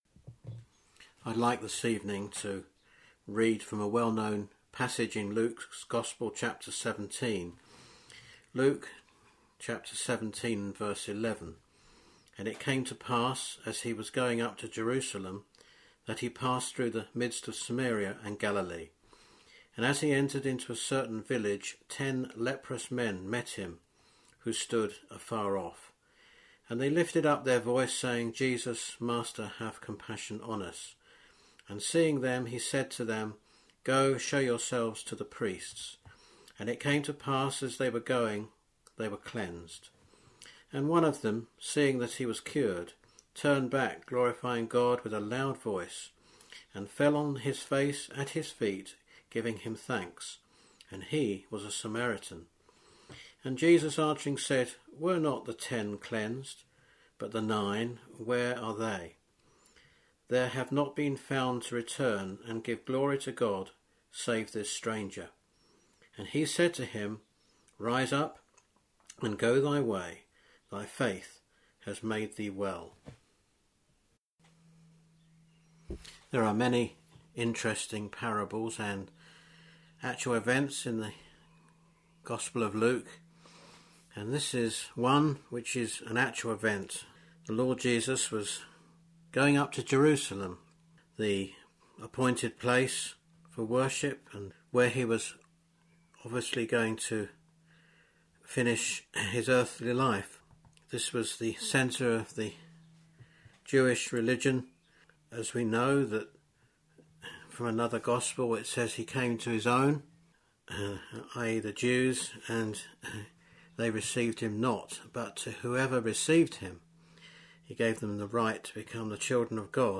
Listen to our Gospel broadcast from Sunday 5th April 2020. In the following Gospel, you will hear what happened when Jesus had an encounter with 10 lepers.